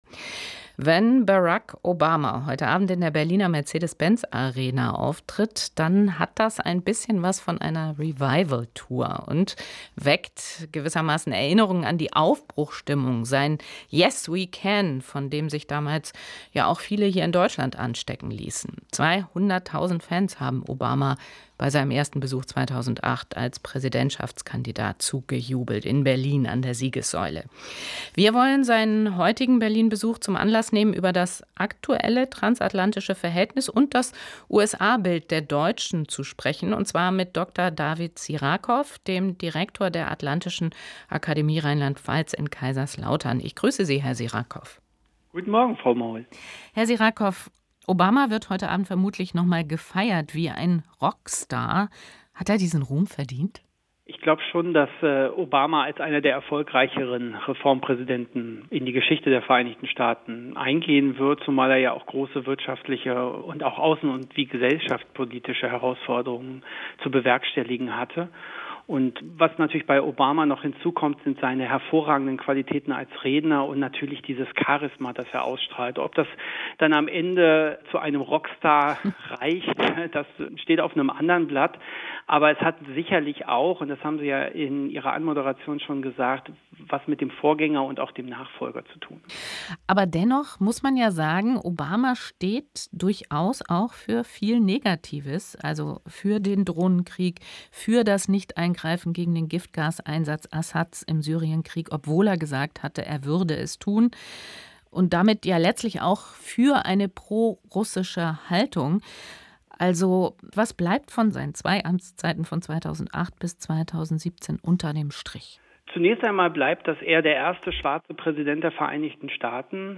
Gespräch SWR2 am Morgen: Obama in Berlin